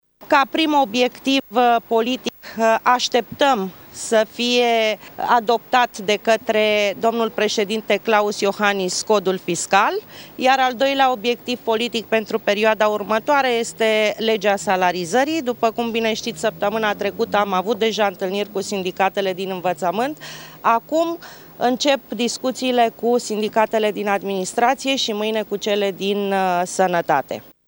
Așa a declarat în urmă cu puțin timp președintele interimar al PSD, Rovana Plumb la ieşirea de la ședința coaliției. Principala prioritate a acesteia este îndeplinirea tuturor angajamentelor asumate prin programul de guvernare: